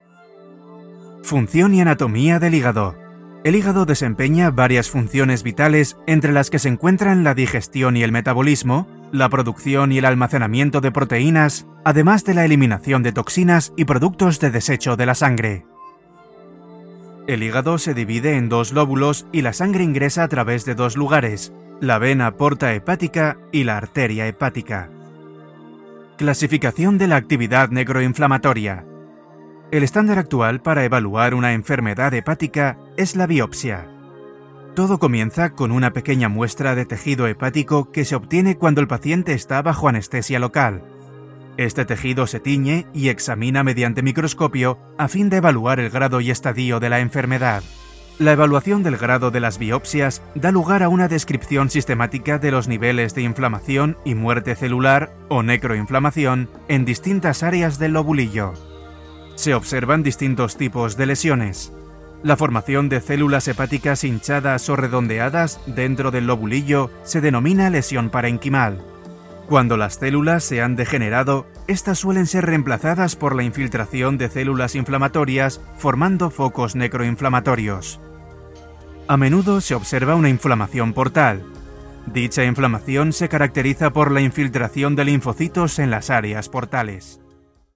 Male
Spanish - Spain (Castilian)
Young, fresh and warm voice, convincing and serious for corporate projects, yet dynamic, informal and upbeat for commercials and TV promos.
E-Learning
Medical Narrations